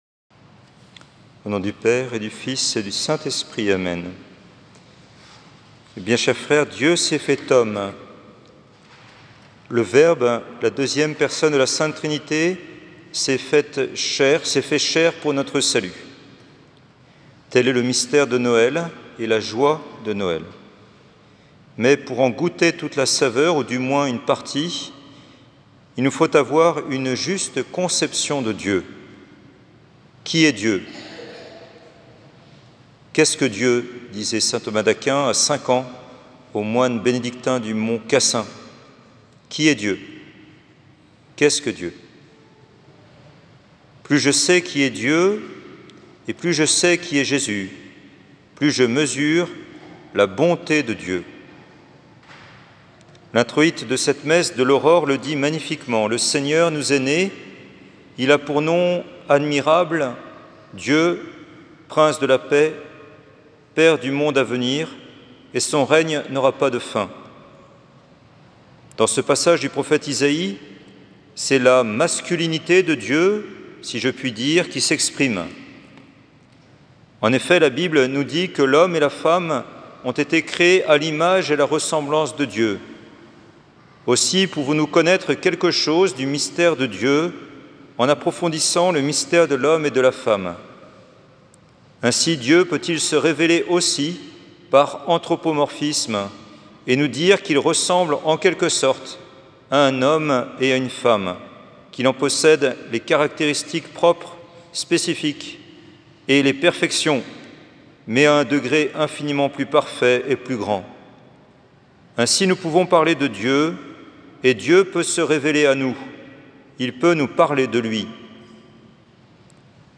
Église catholique Saint-Georges à Lyon
Homélies du dimanche